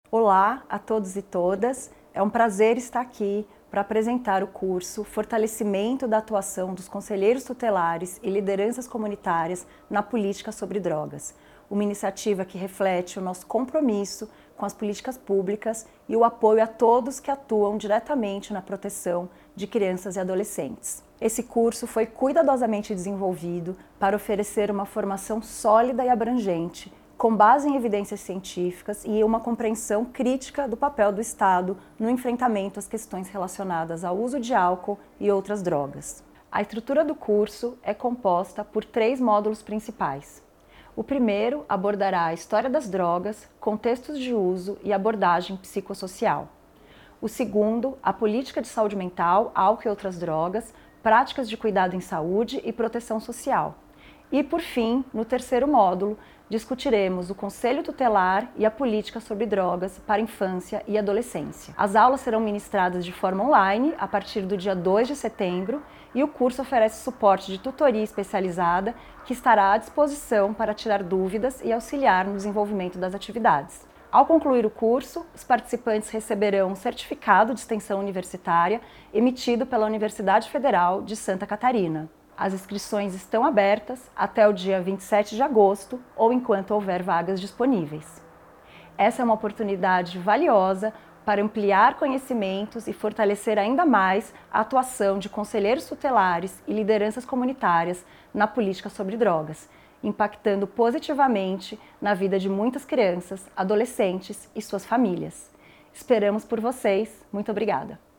Fala da secretária Marta para a abertura do curso Fortalecimento da Atuação dos Conselheiros Tutelares e Lideranças Comunitárias na Política Sobre Drogas.mp3 — Ministério da Justiça e Segurança Pública